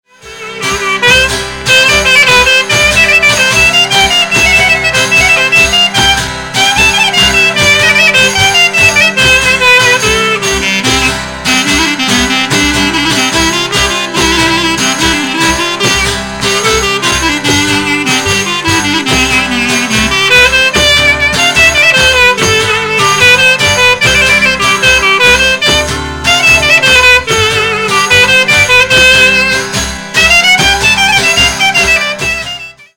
Syrtos
lavouto